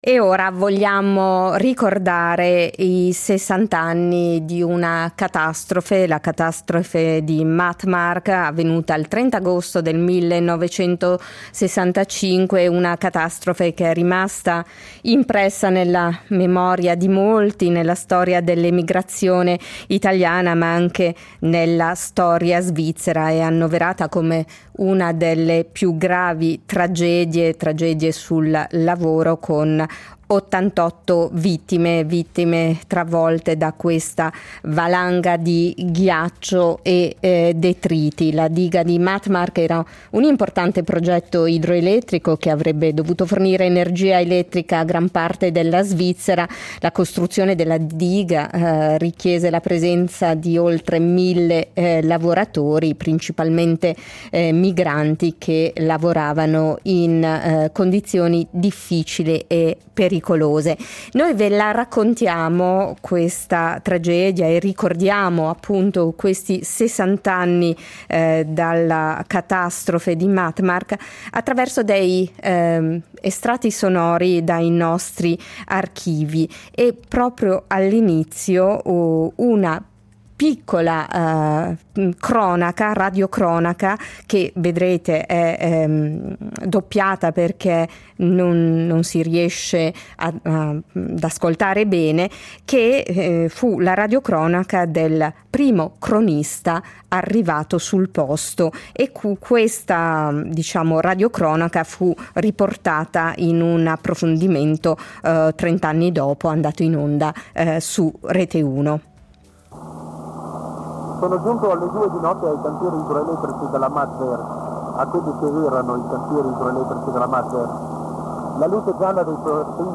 Dagli archivi RSI alcune testimonianze che raccontano la tragedia accaduta 60 anni fa quando una valanga investì il cantiere per la costruzione della diga di Mattmark nel Canton Vallese, causando 88 morti.